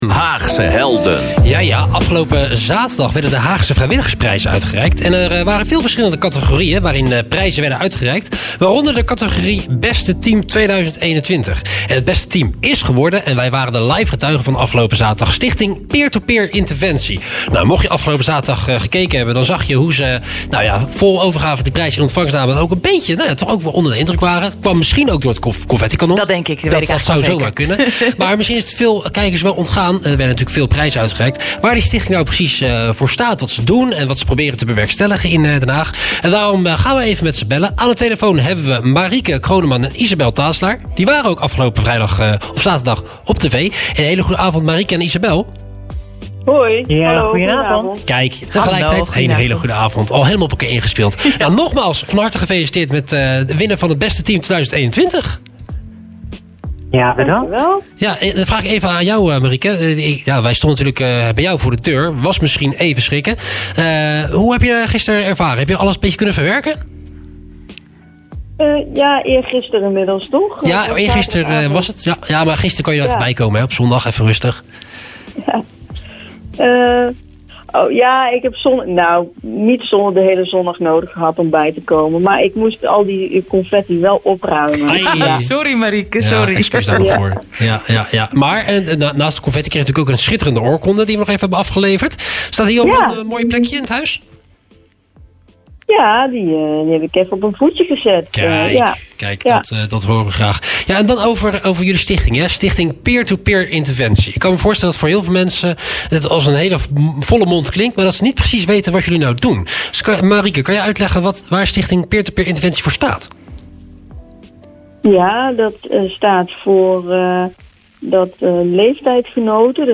Interview-Stichting-SPINT-Haagse-Helden-Den-Haag-FM.mp3